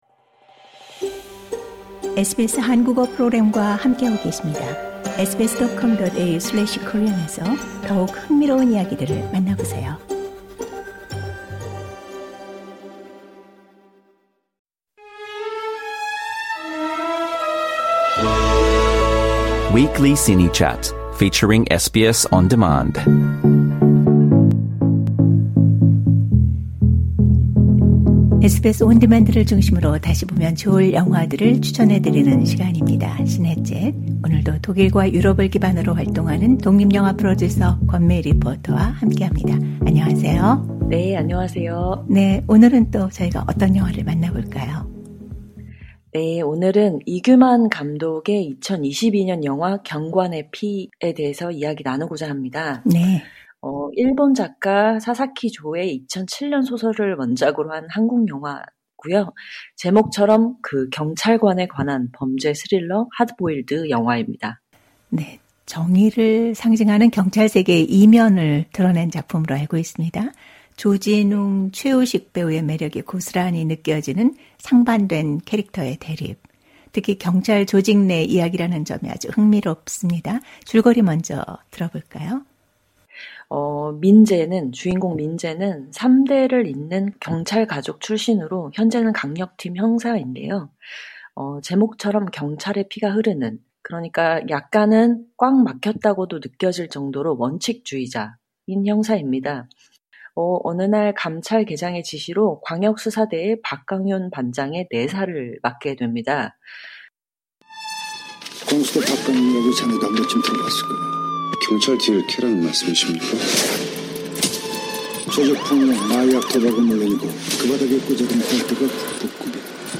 Trailer Audio Clip 일종의 언더커버 경찰로서 광수대에 투입이 되고 민재는 강윤의 수사 과정을 도우며 비밀리에 그의 비리 혐의를 밝혀내기 위해 임무를 수행하는데요.